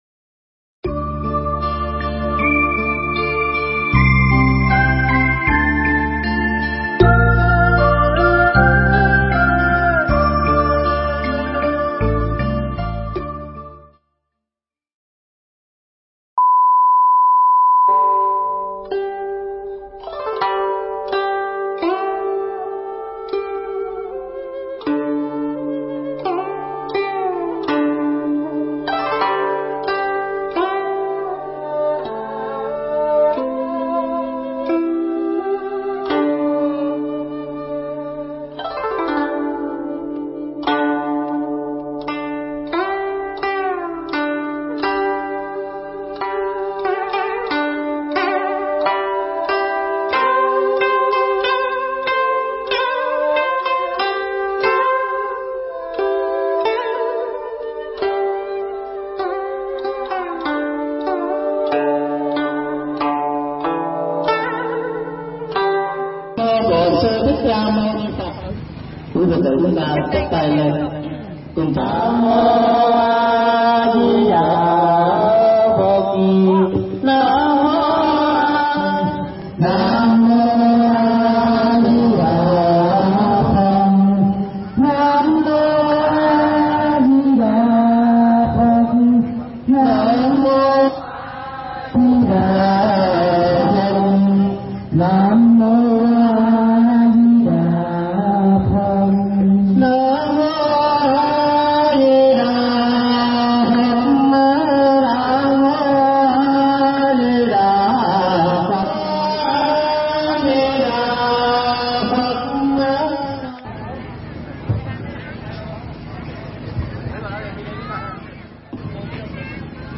Thuyết pháp
giảng nhân ngày Bồ Tát Quán Thế Âm thành đạo tại chùa Đại Giác (tp.Đồng Hới, Quảng Bình)